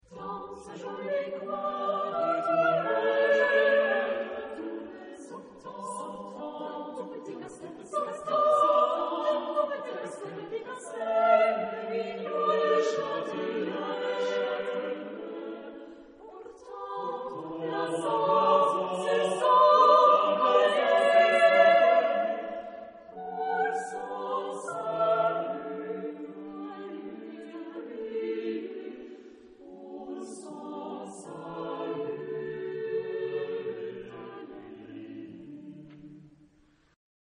SATB (4 voices mixed) ; Full score.
Poetical song.